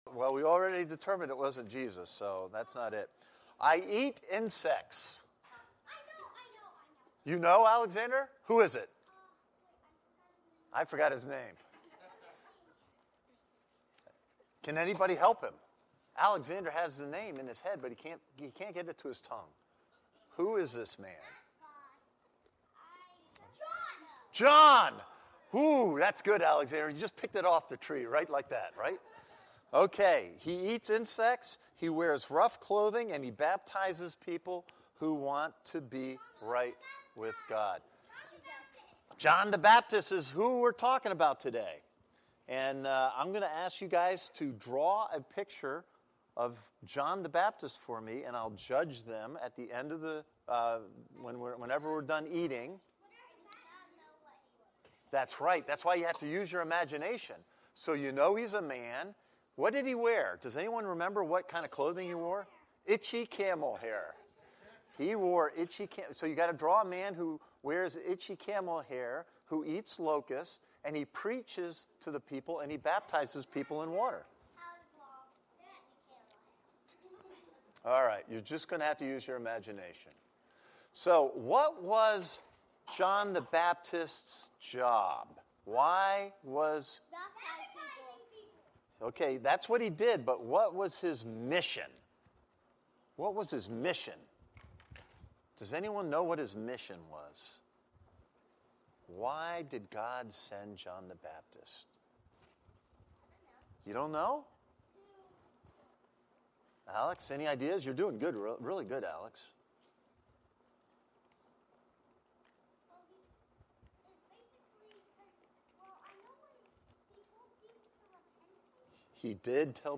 A message from the series "Sunday Service." May unity be forged in difficulty...
This is the main Sunday Service for Christ Connection Church